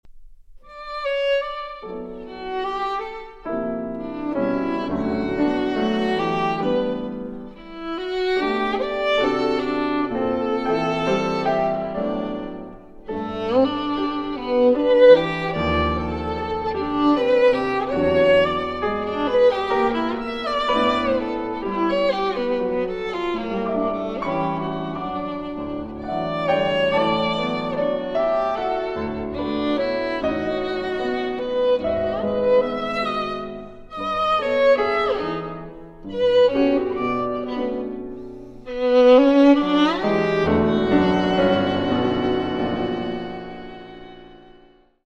Sonata for Violin and Piano (1963) (22:57)
Andantino (with simplicity) (6:35)